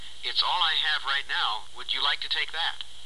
英語發音 English Pronunciation
(加連線者為連音，加網底者不需唸出聲或音很弱。)